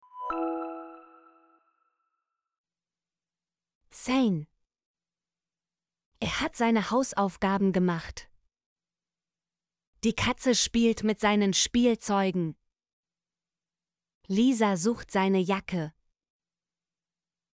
seine - Example Sentences & Pronunciation, German Frequency List